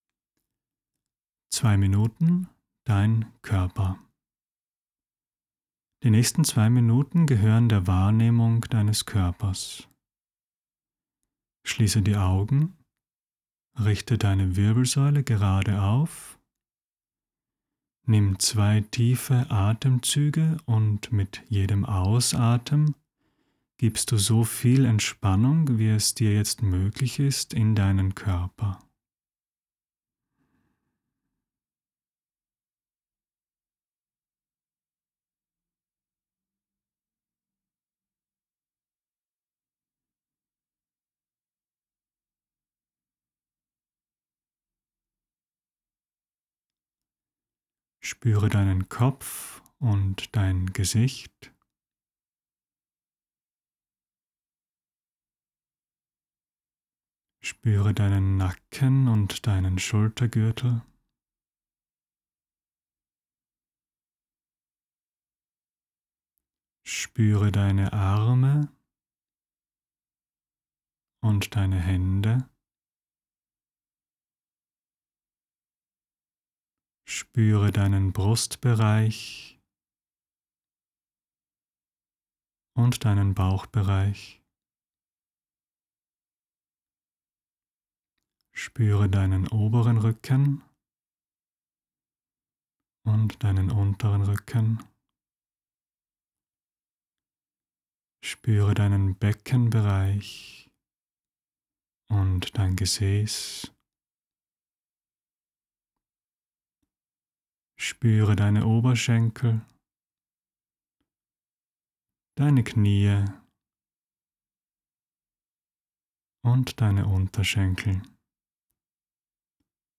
Ein minimalistischer Body Scan für Tage mit ganz wenig Zeit. Audioanleitung und Erklärung zum Lesen.